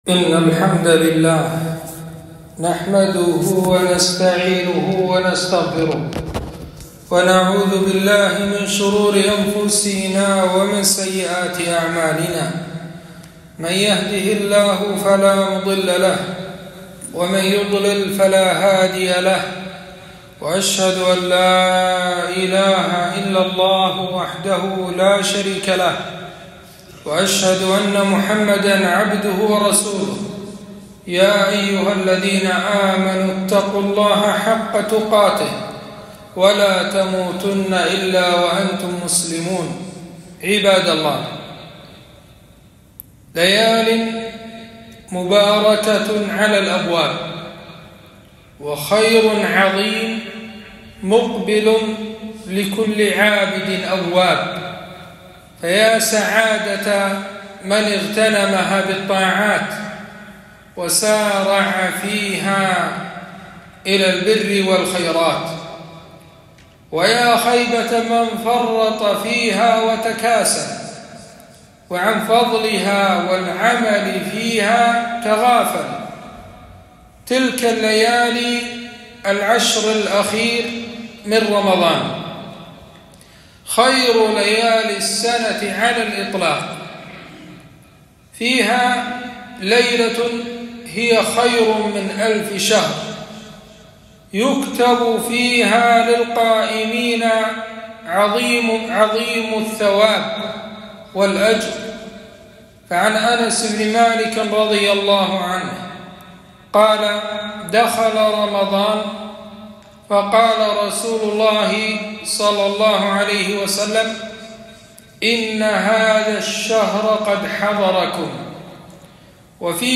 خطبة - فضائل العشر الأواخر من رمضان